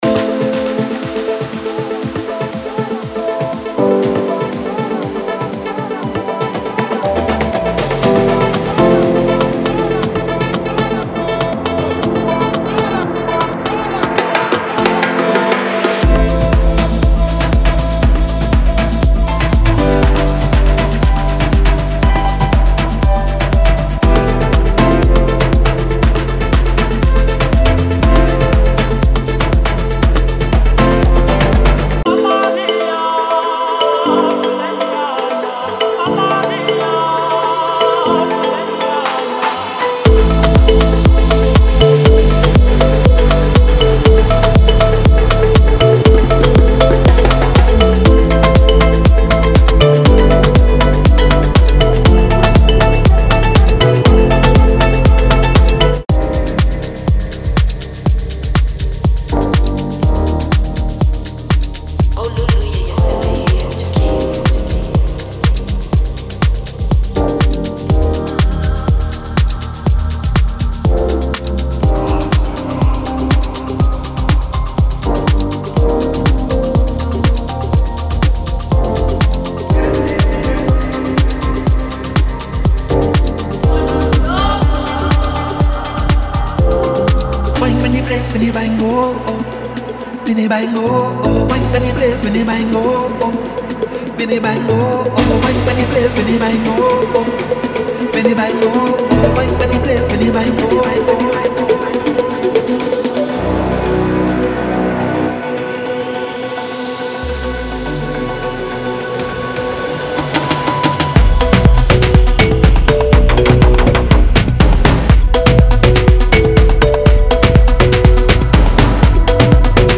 Melodic Techno Tribal